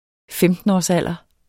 Udtale [ ˈfεmdənɒs- ]